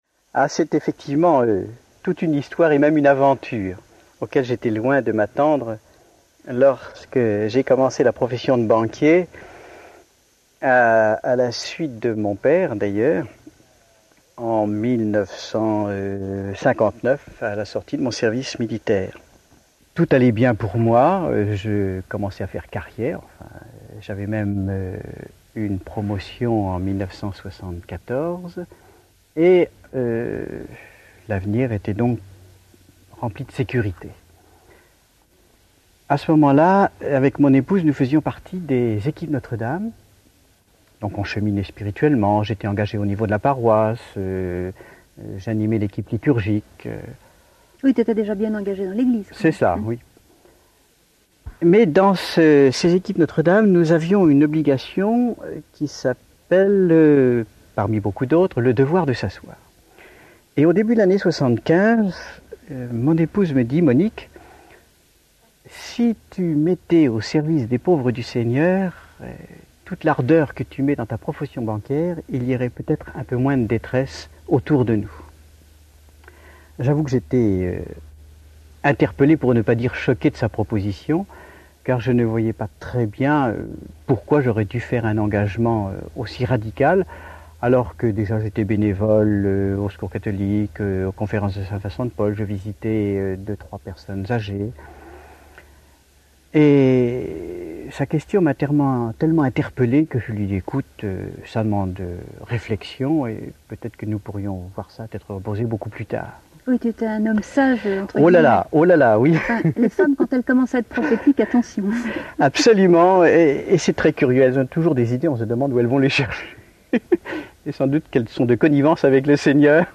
MP3 64Kbps Mono Taille